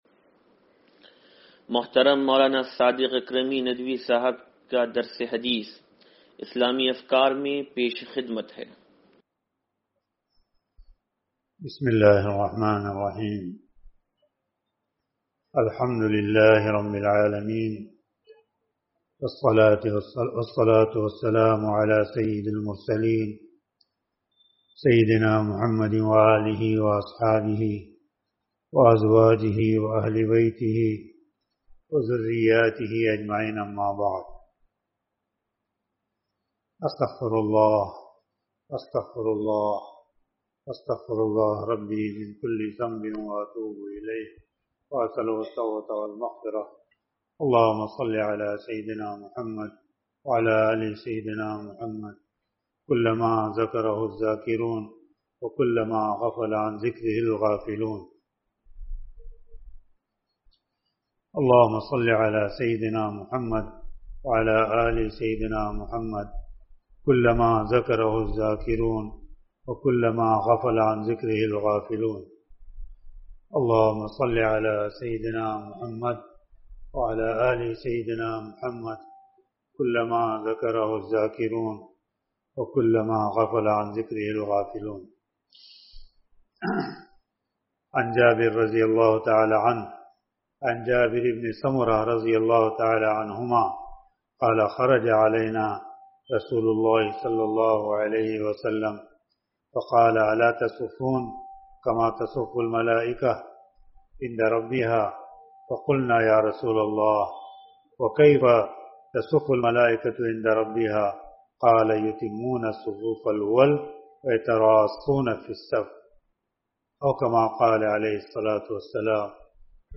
درس حدیث نمبر 0820